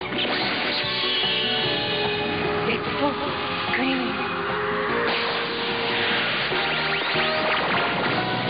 Dead Scream : Setsuna whispers this phrase before her attack.
Deadscrm.mp2 - Pluto's dead scream attack!
deadscrm.mp2